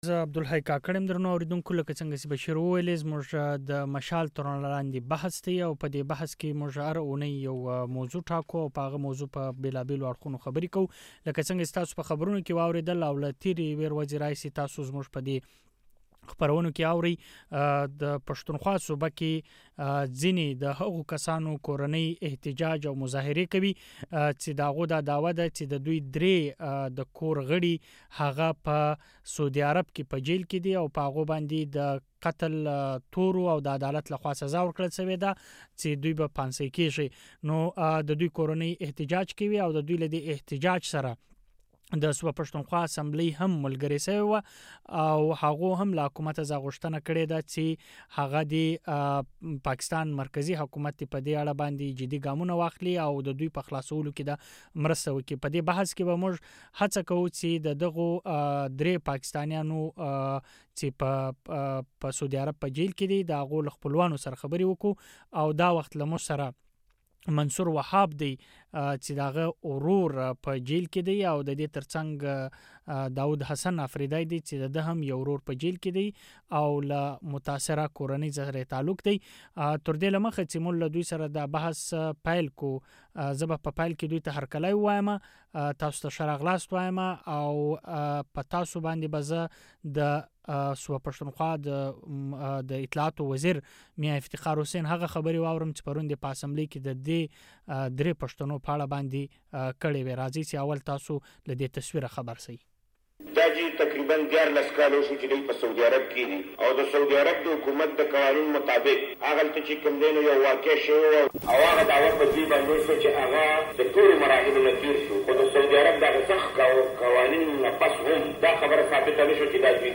هم په دې موضوع باندې د مشال تر رڼا لاندې بحث شوی